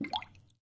气泡01
描述：泡泡水是用装满空气的瓶子做成的在水槽的水下这个泡泡很好很甜用sony MD录音机和立体声麦克风录制
Tag: 气泡